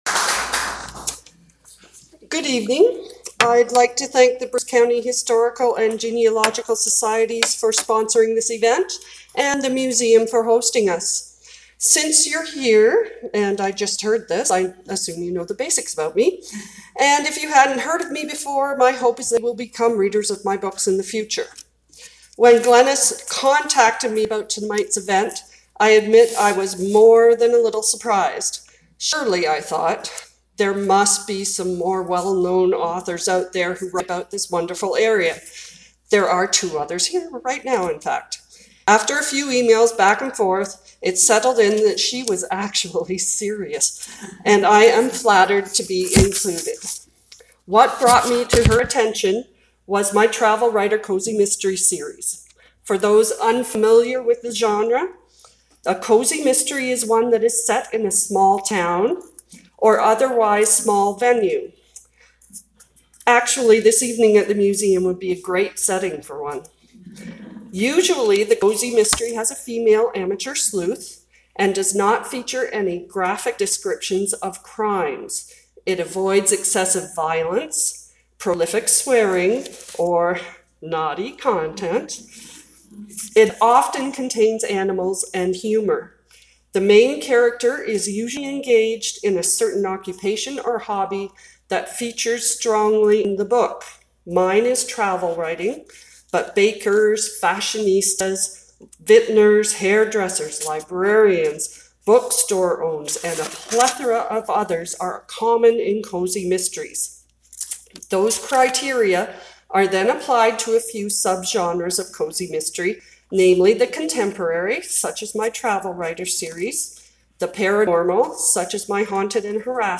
Museum Speech: Text Version